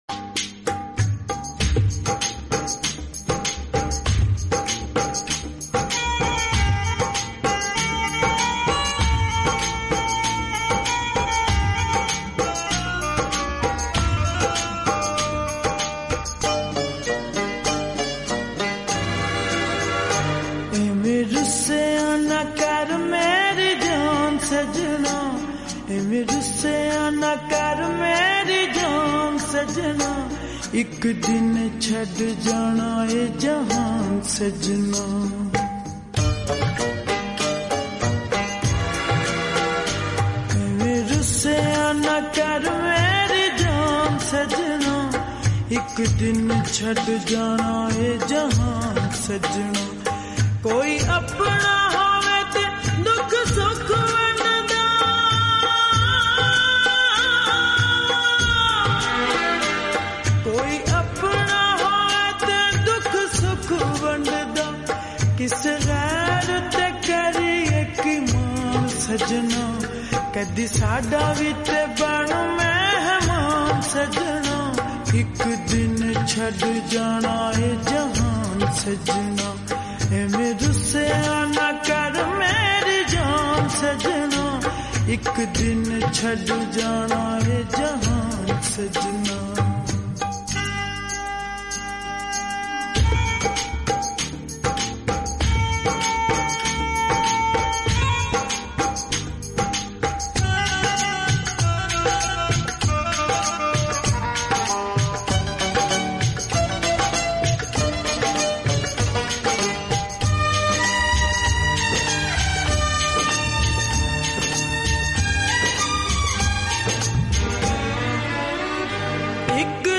full sad song